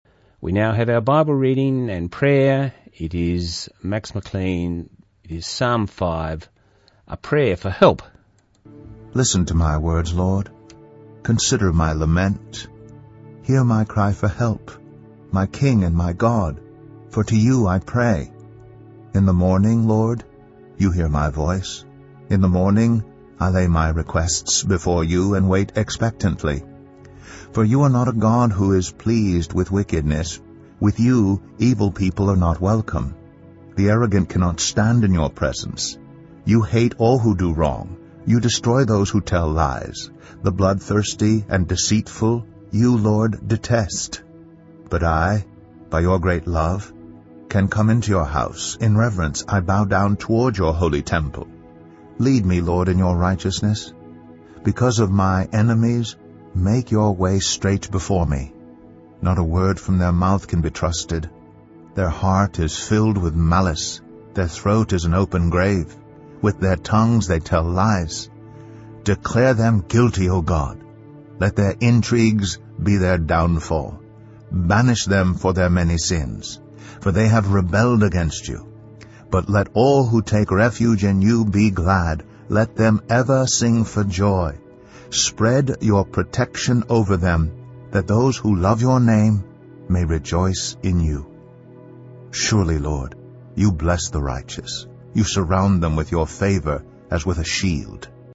Our prayer podcast for 12 October 2014 was Psalm 5, read by Max McLean. It is a prayer for help. Broadcast on Songs of Hope program, Southern FM 88.3 on Sunday 12/10/2014